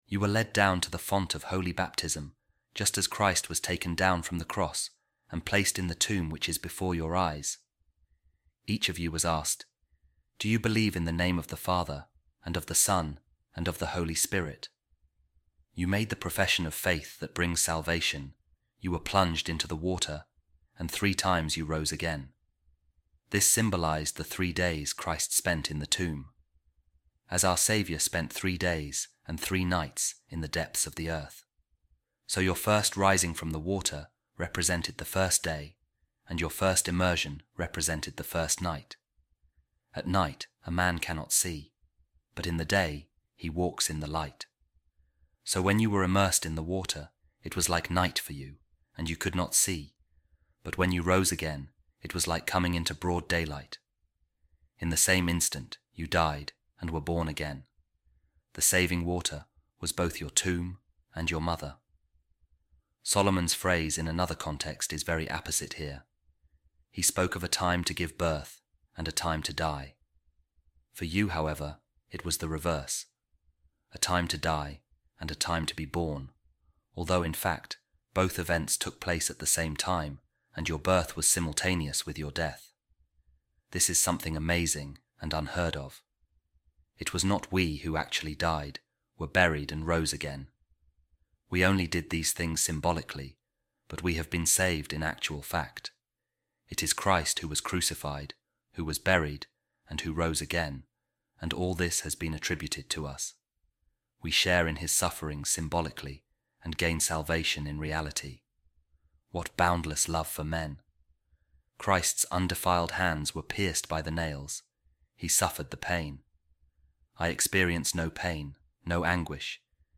Office Of Readings | Easter Thursday | A Reading From The Instructions To The Newly Baptized At Jerusalem | Buried In Water, Born Into Light